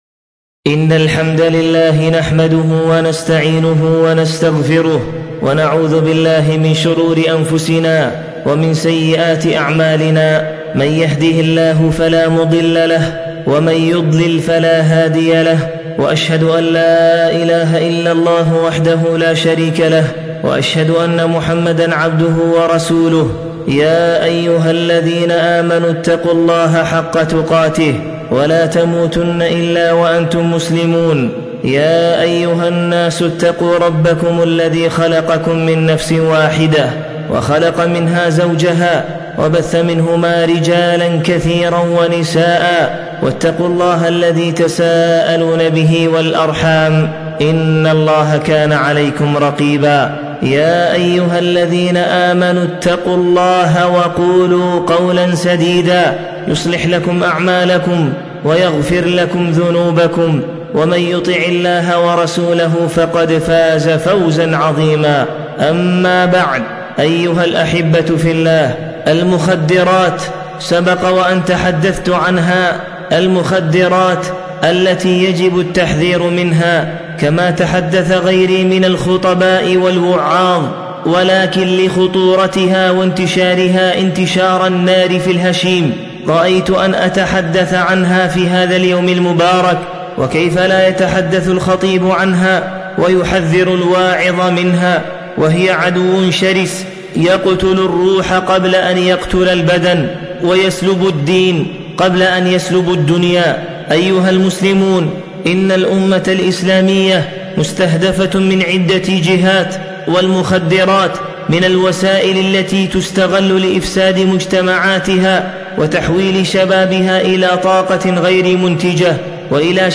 خطبة الجمعة - المخدرات و مخاطرها